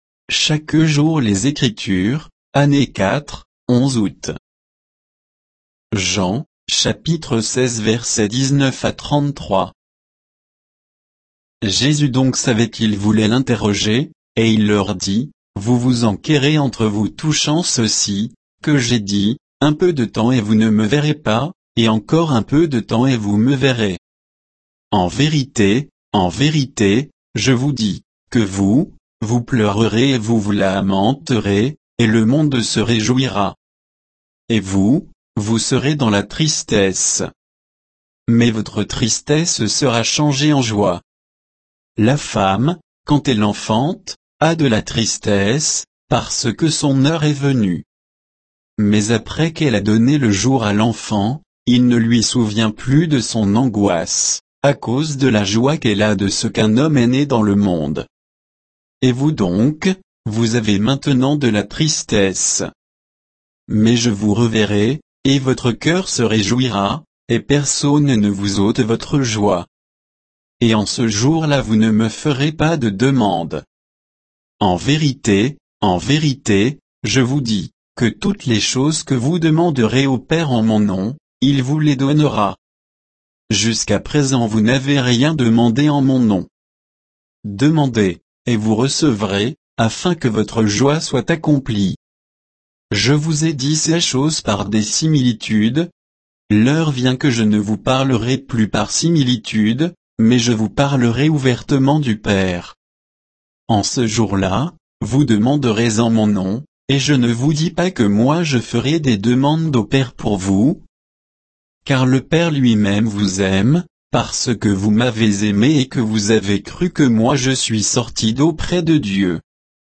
Méditation quoditienne de Chaque jour les Écritures sur Jean 16